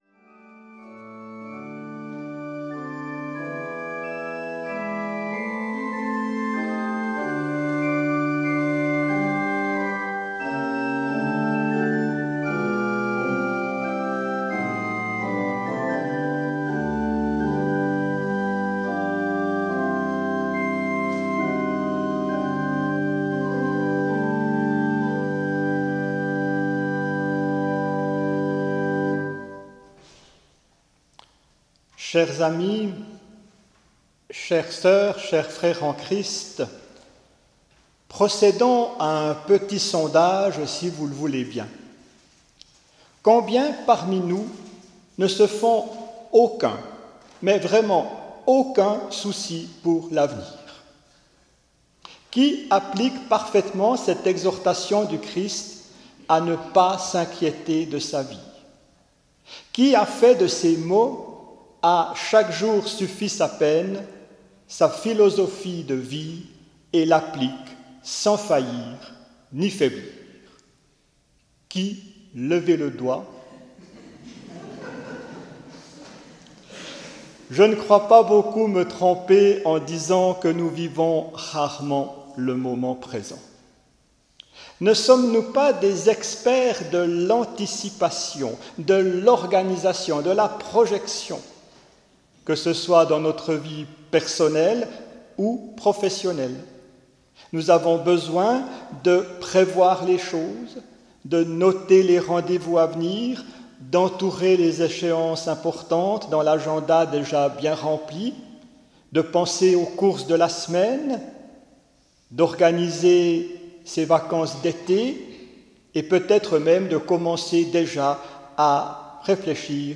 Cette prédication est en lien avec la Lanterne, le lieu d’accueil de l’aumônerie œcuménique de rue en Ville de Neuchâtel.